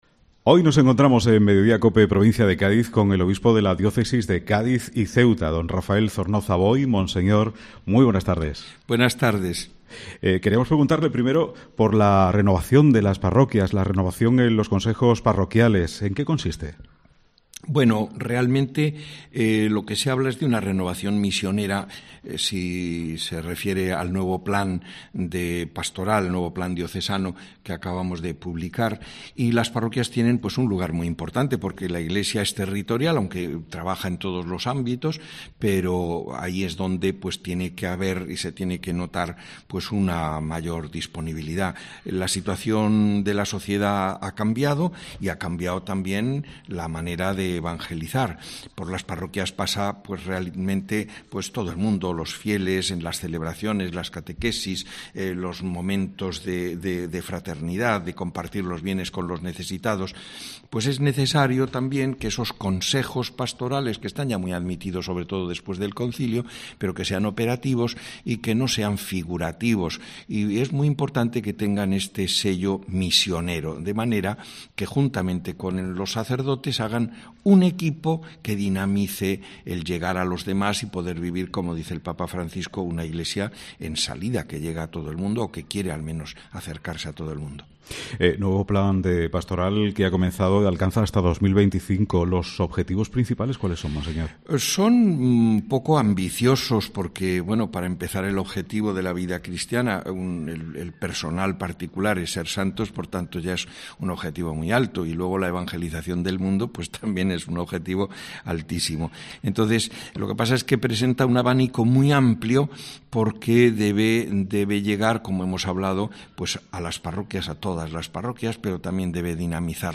El obispo de la Diócesis de Cádiz y Ceuta hace balance y mira al presente y futuro de la Iglesia en los micrófonos de COPE Cádiz